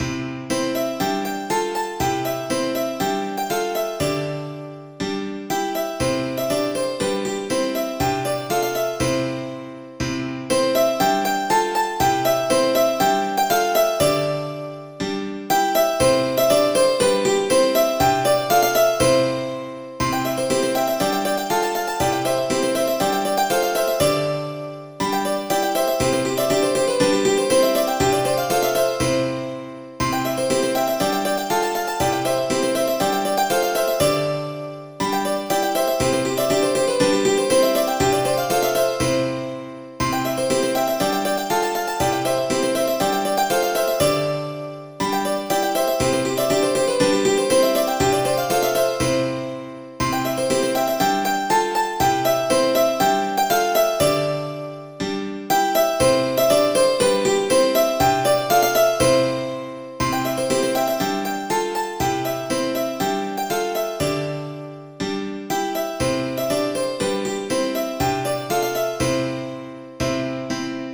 Midi File, Lyrics and Information to Johnny Todd
johntodd.mid.ogg